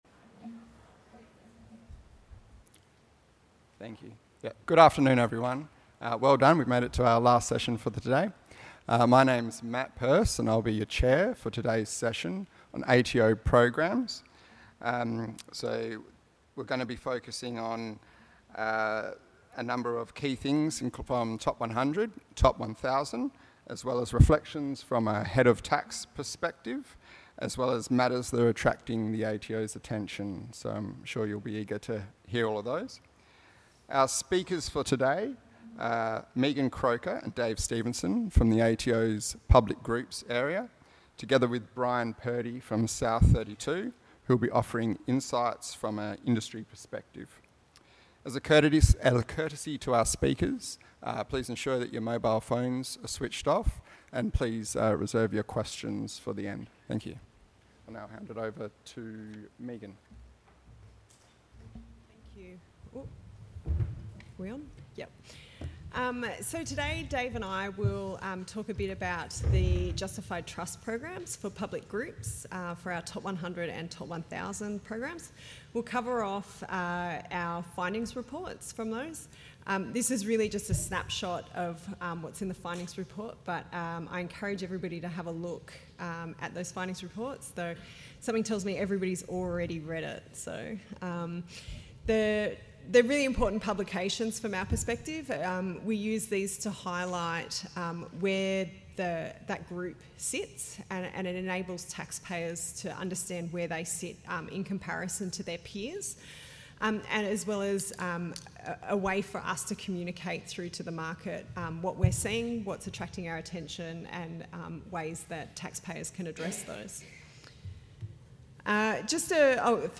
ATO programs – A panel discussion
Event Name: National Resources Tax Conference
Took place at: The Westin Perth